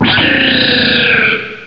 cry_not_cobalion.aif